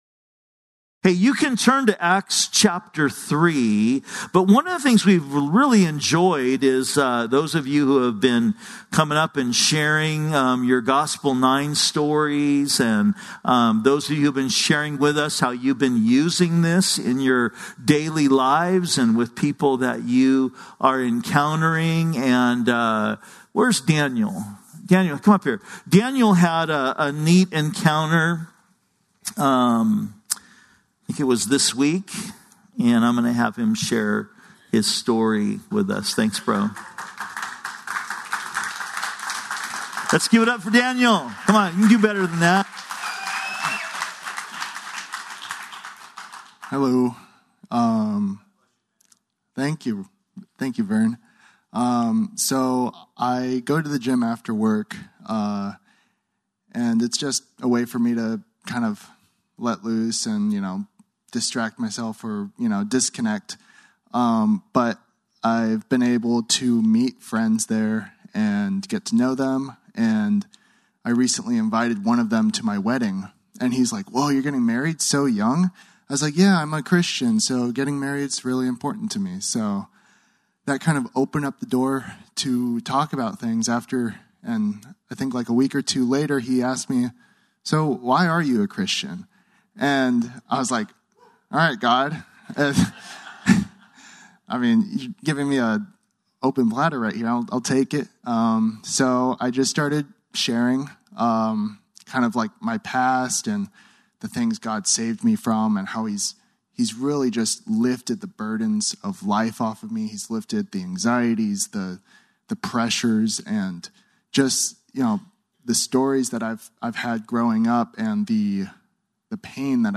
Wednesday Bible studies